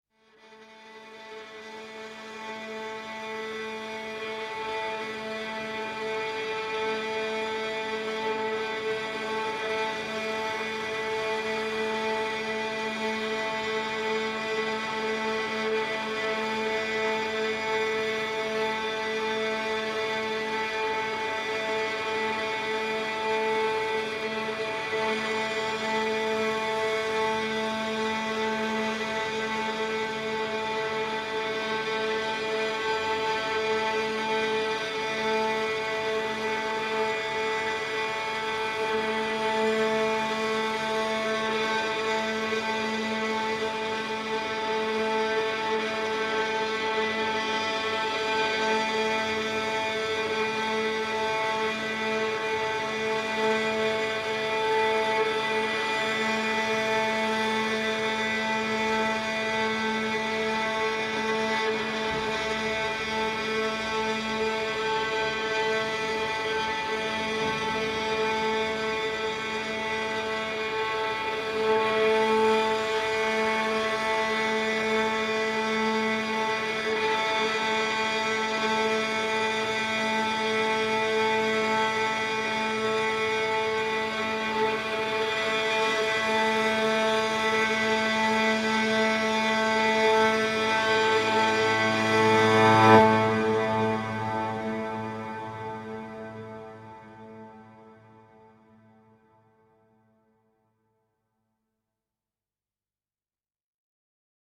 String Drone Version of STR 065 003.